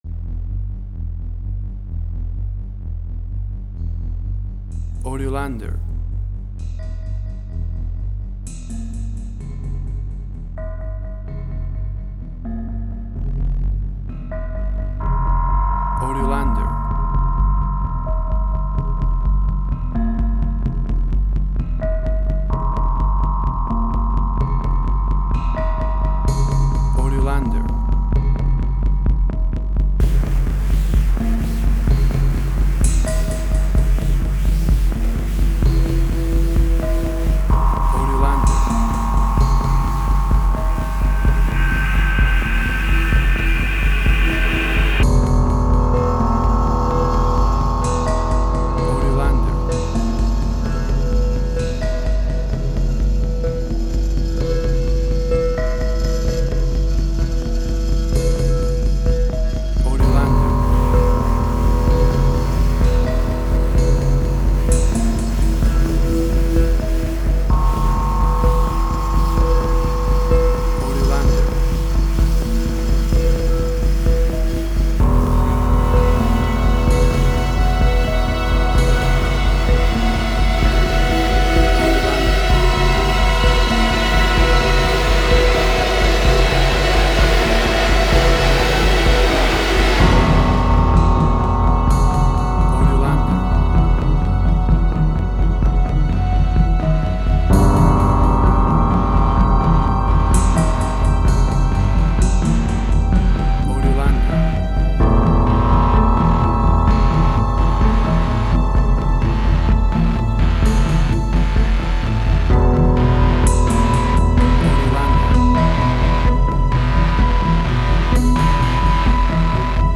Spaghetti Western, similar Ennio Morricone y Marco Beltrami.
Tempo (BPM): 128